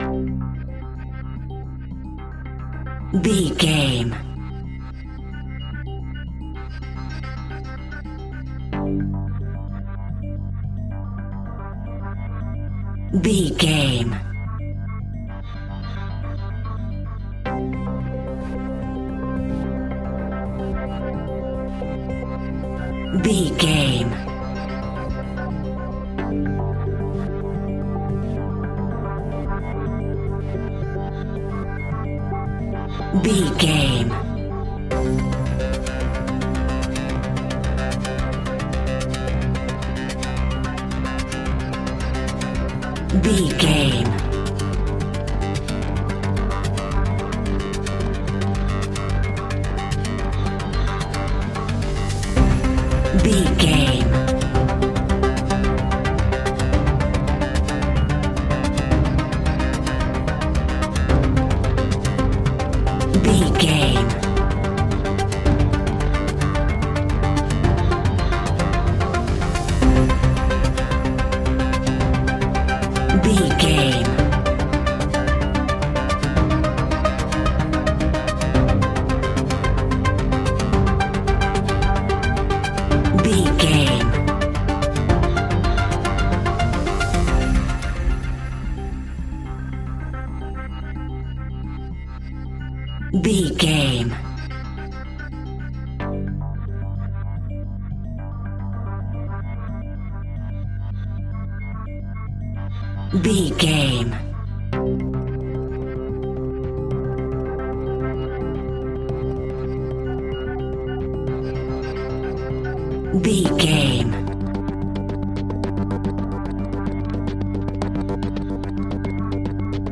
In-crescendo
Thriller
Aeolian/Minor
ominous
haunting
eerie
tense
instrumentals
horror music
Horror Pads
horror piano
Horror Synths